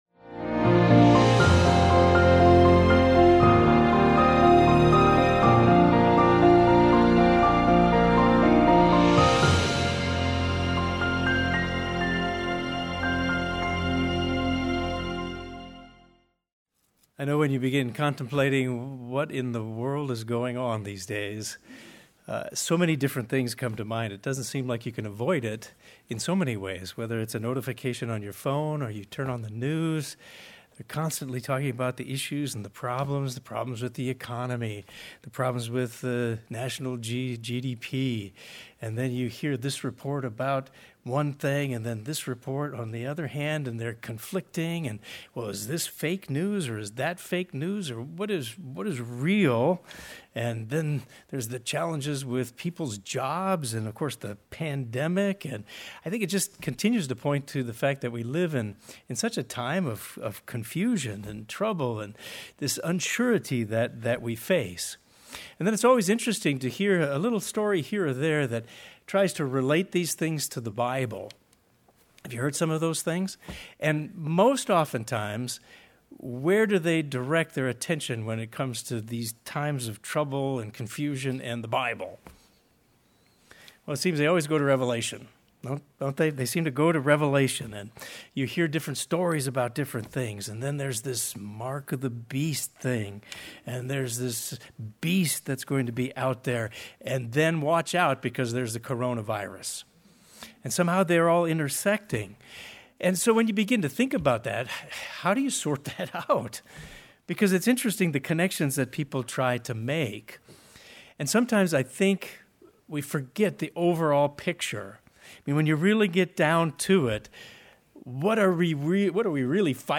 This sermon delves into Revelation to answer those questions from a Biblical perspective.